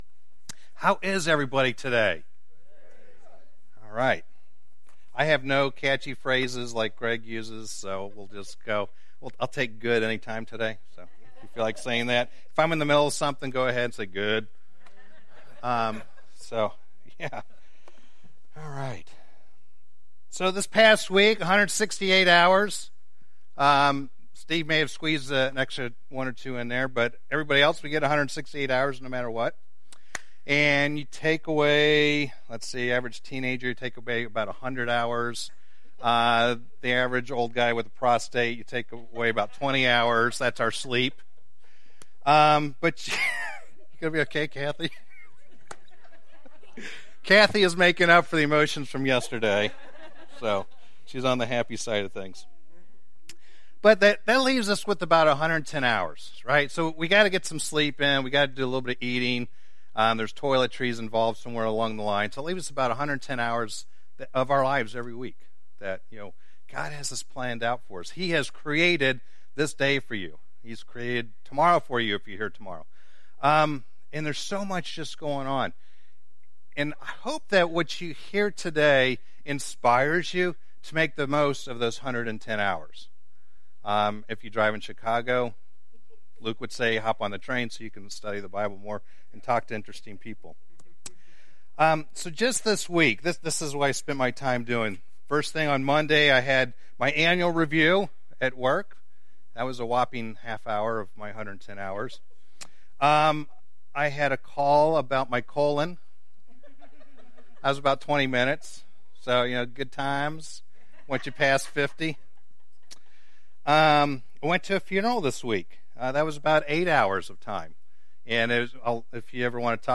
Sermons that are not part of a series